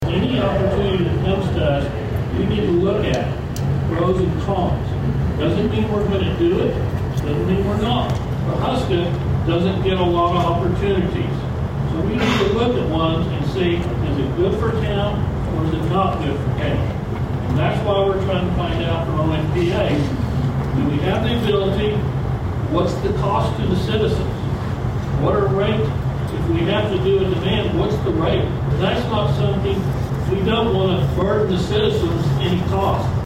In a standing room only crowd that reached full capacity at the Dave Landrum Community Center, 14 Pawhuska residents spoke out against the possibility of a small scale data center coming to Pawhuska.
Following a nearly two hour discussion, Mayor Steve Tolson said the council is a long way from making a decision